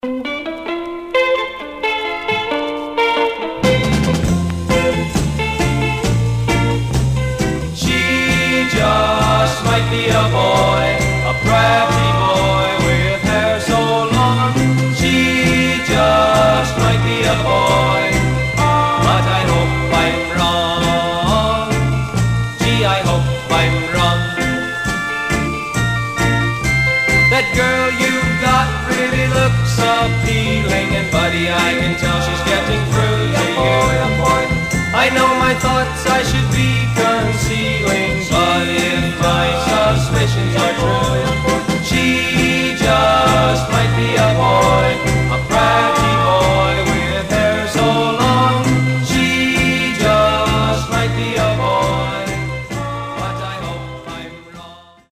Stereo/mono Mono
Garage, 60's Punk